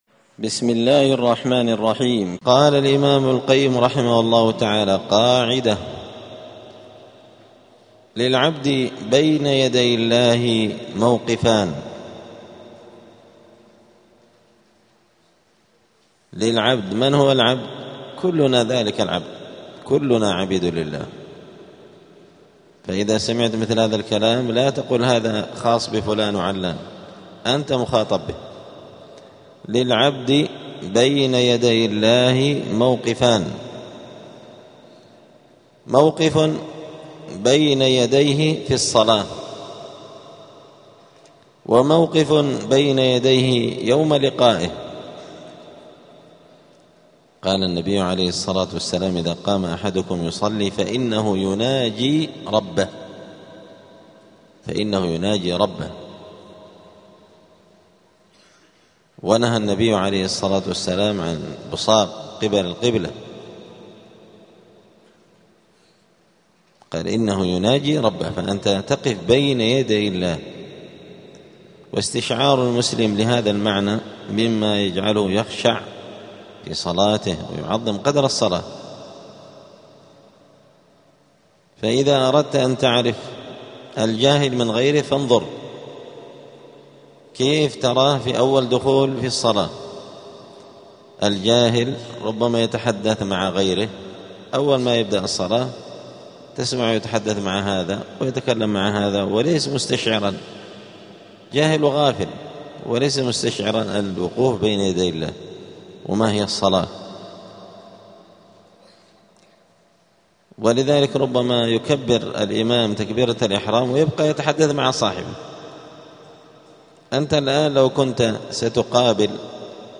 دار الحديث السلفية بمسجد الفرقان قشن المهرة اليمن
الدروس الأسبوعية